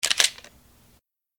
pause-back-click.ogg